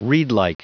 Prononciation du mot reedlike en anglais (fichier audio)
Prononciation du mot : reedlike